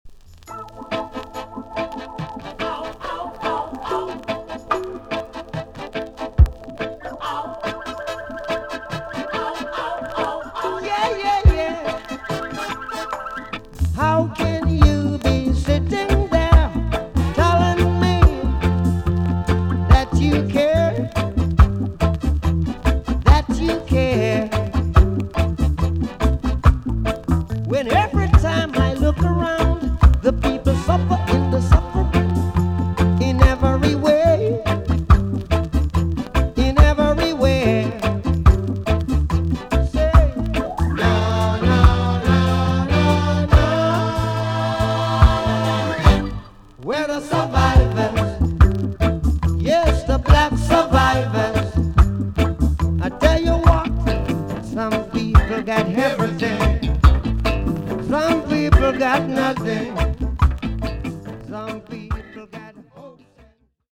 B.SIDE EX-~VG+ 少しチリノイズの箇所がありますが音は良好です。